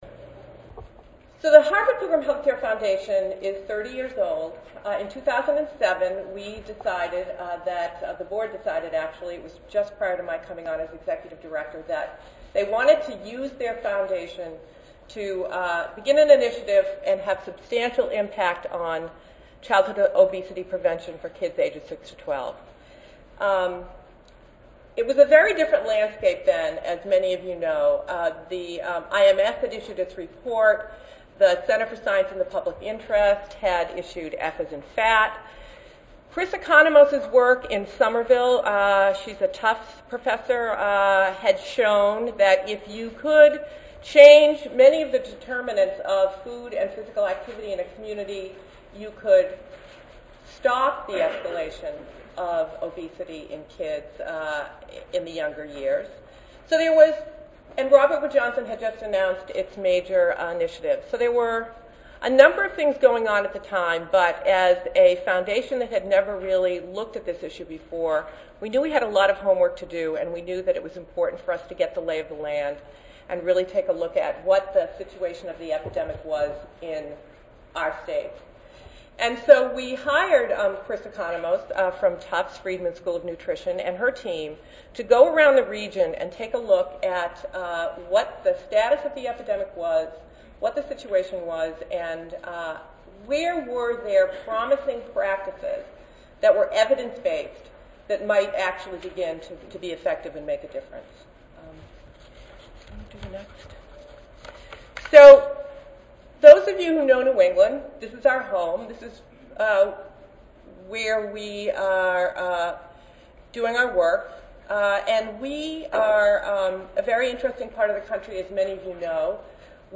The purpose of this presentation is to hear about these programs and what the Foundation learned from this five year effort.